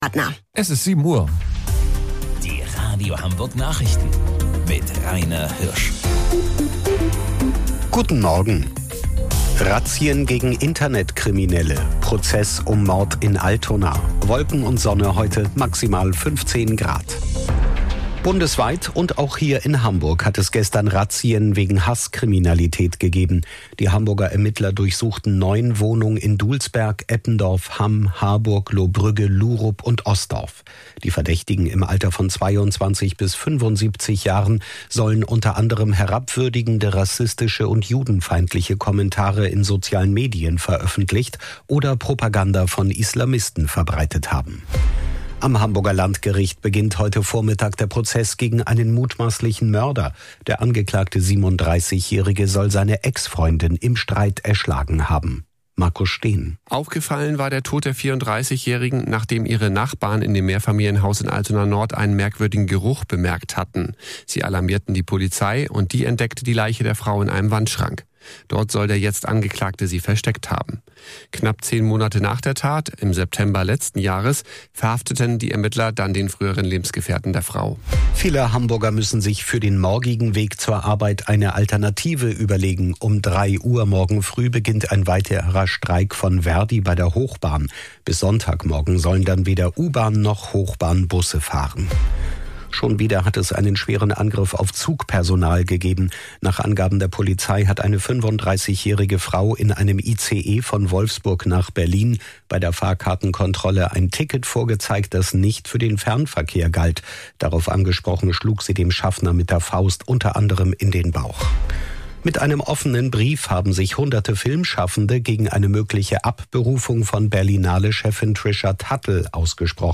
Radio Hamburg Nachrichten vom 26.02.2026 um 07 Uhr